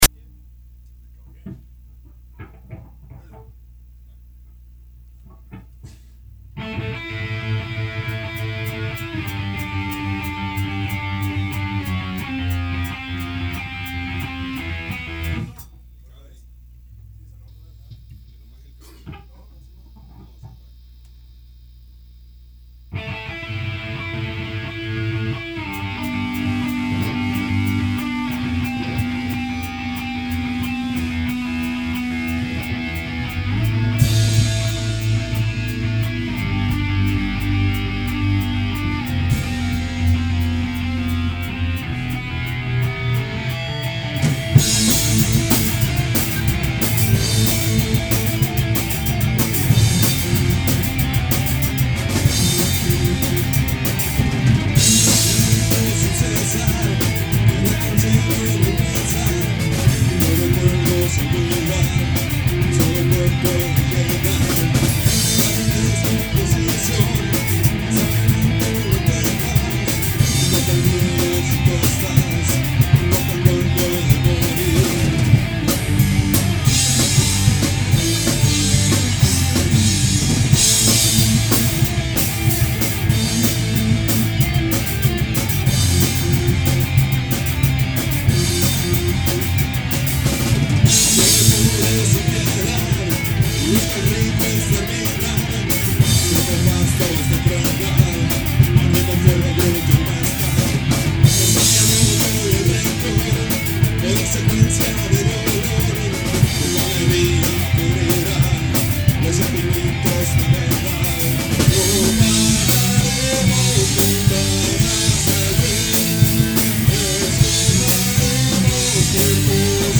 Banda de rock progresivo, con un año de haber iniciado.
Progressive rock